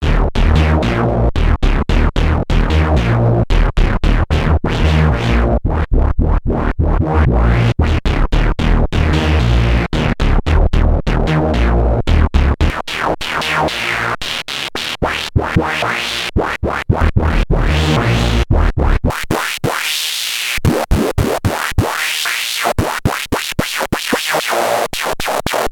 Filter experiments
Sound example for different filter types (Lowpass, Bandpass, Low/Bandpass, Highpass:
The distortion of the filter which happens when all three oscillators are playing is one of the major features of the SID chip which makes it so unique.